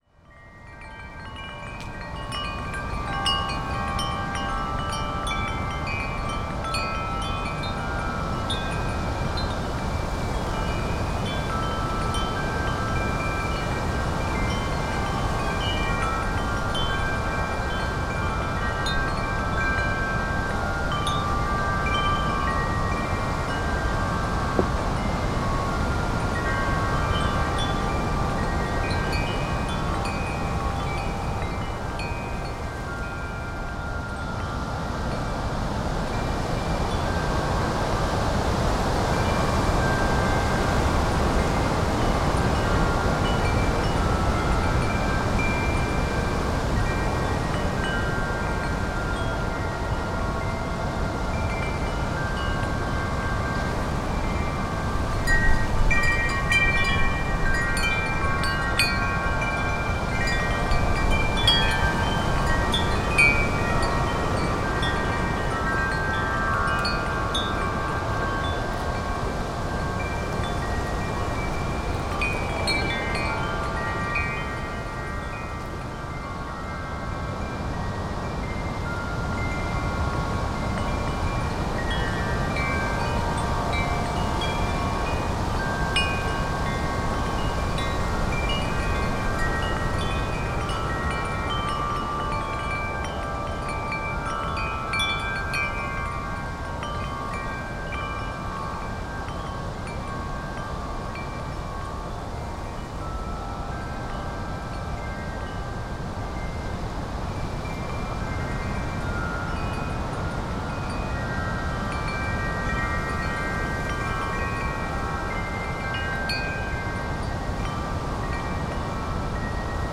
Wind Chimes in GALE over wooded valley - Pluto - Gypsy Soprano + Mezzo - excerpt
Category 🌿 Nature
chimes Devon Drewsteignton England field-recording gale gusts Gypsy sound effect free sound royalty free Nature